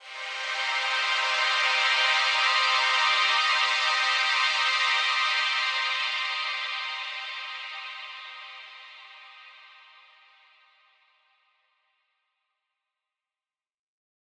SaS_HiFilterPad07-C.wav